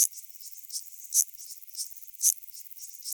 Data resource Xeno-canto - Orthoptera sounds from around the world